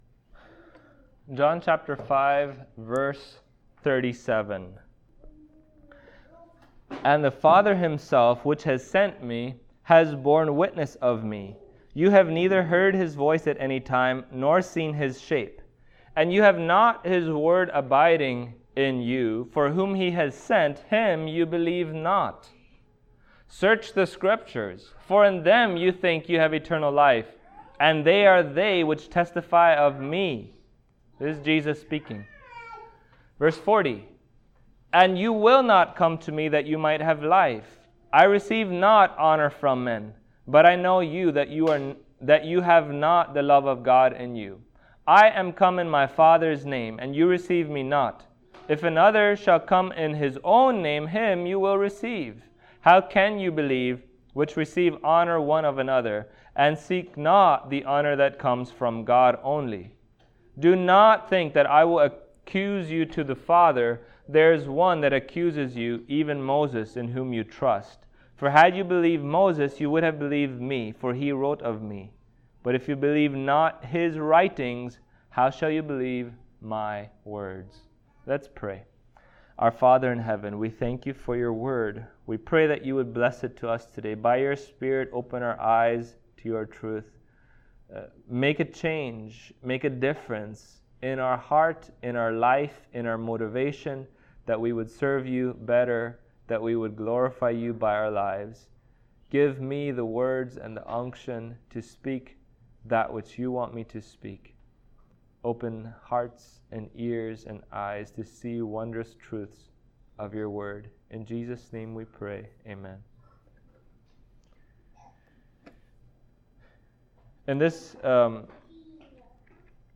John Passage: John 5:37-47 Service Type: Sunday Morning Topics